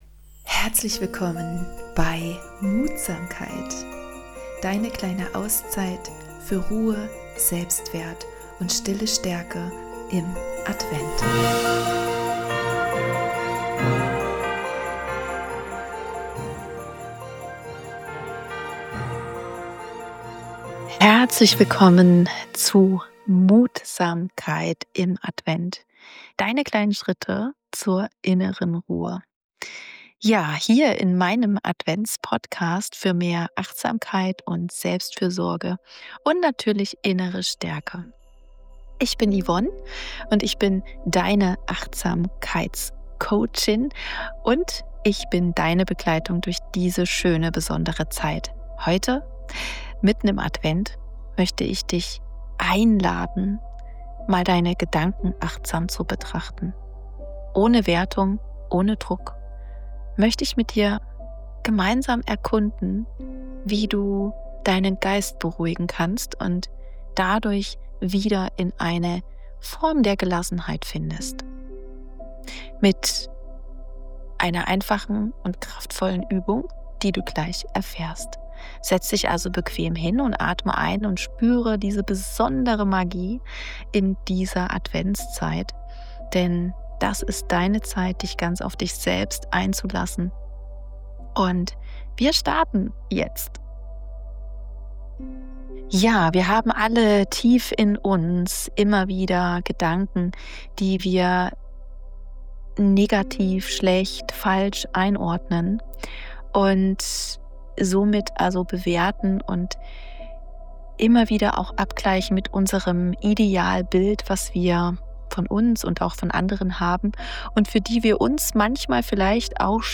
Egal, ob du gerade bei einer Tasse Tee sitzt oder dich von der Hektik des Alltags erholen möchtest, diese Meditation schenkt dir einen Moment der Stille und Besinnung.